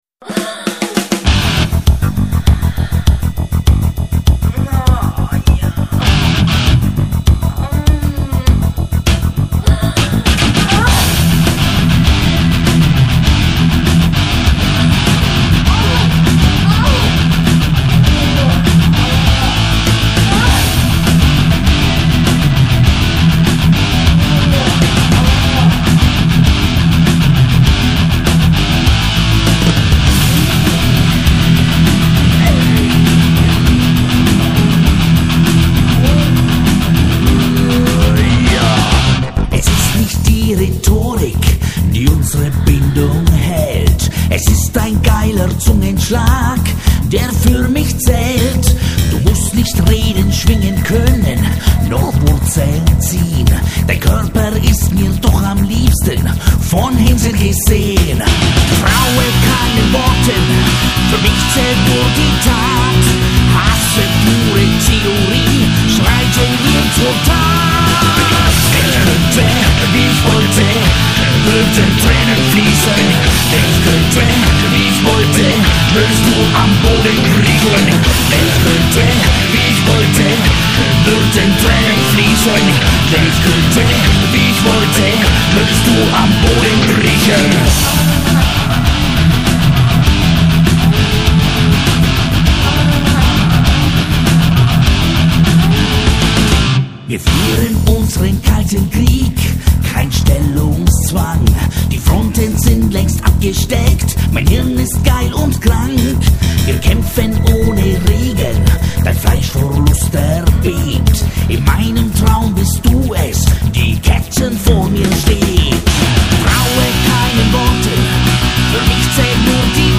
Deutschrock